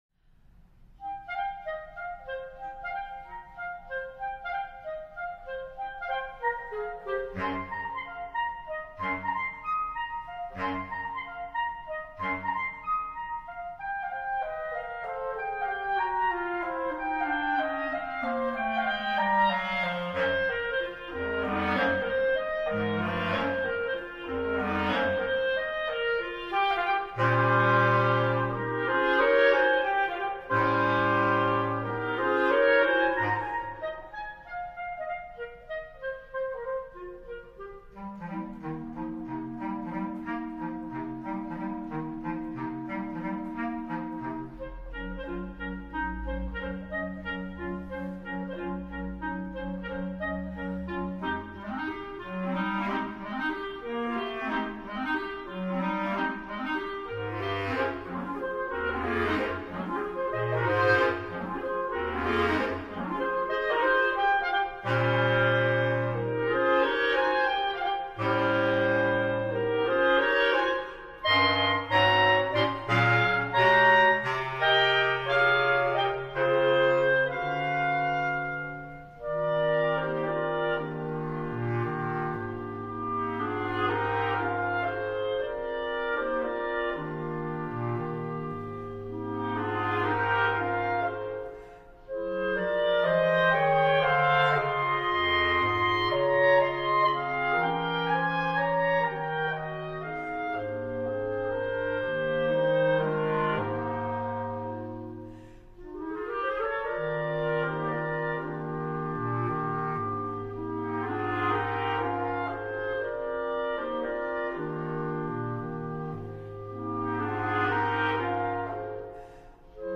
单簧管五重奏